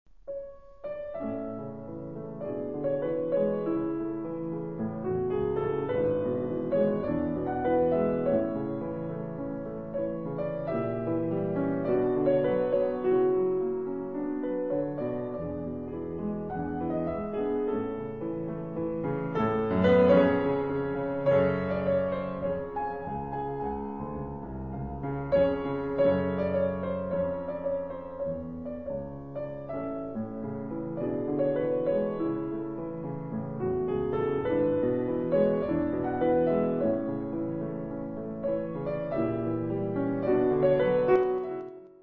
Par ailleurs, la souplesse de son phrasé fait merveille dans le premier et le troisième mouvement de la sonate.
Sonate pour piano en la majeur op. posth. 120 D. 664